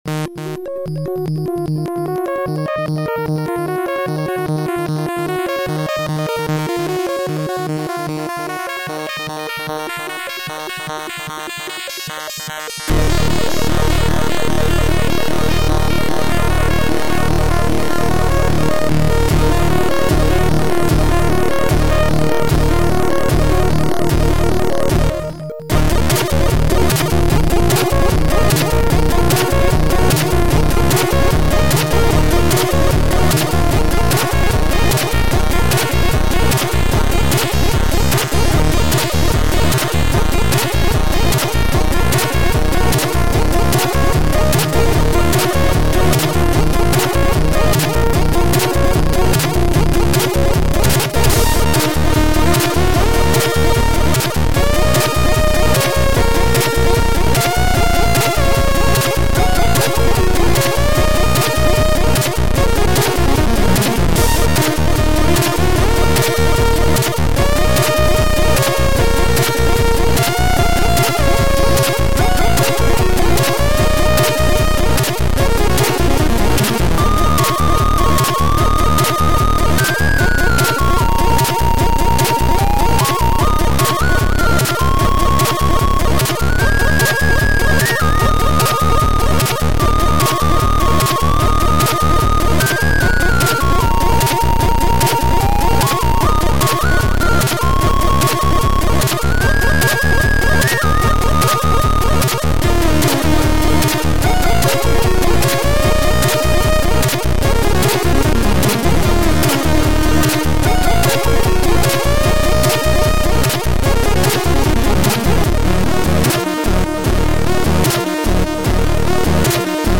Sound Format: Abyss Highest eXperience